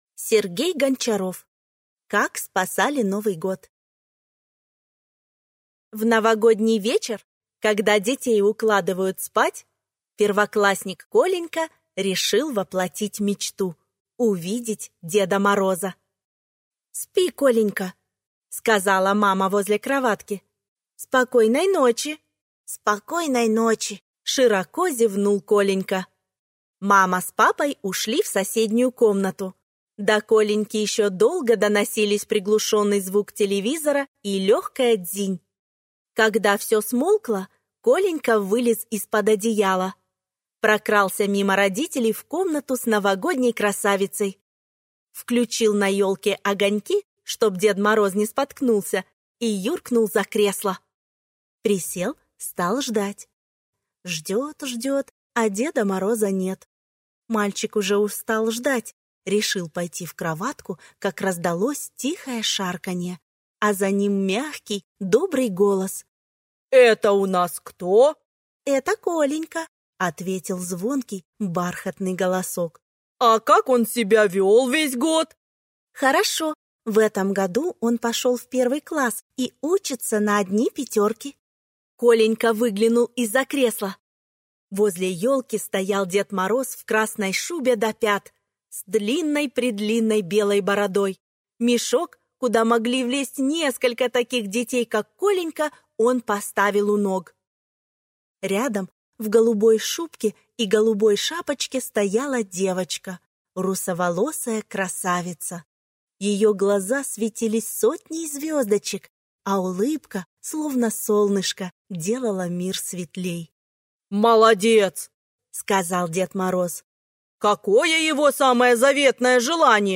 Аудиокнига Как спасали Новый год | Библиотека аудиокниг
Прослушать и бесплатно скачать фрагмент аудиокниги